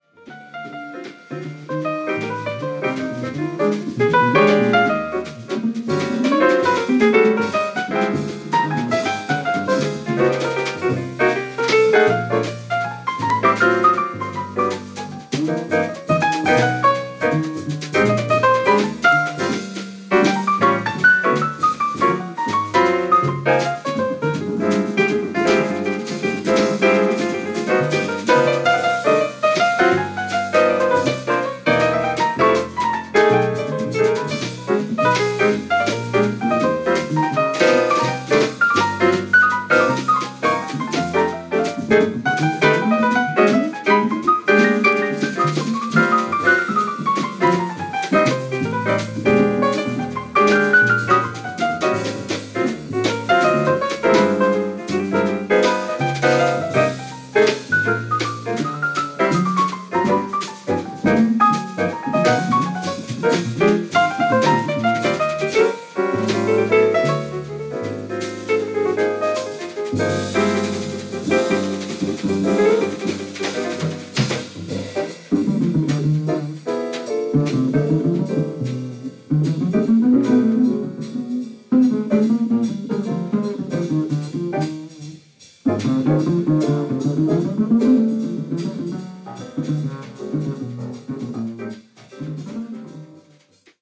７４年・Village Vanguardでの録音です。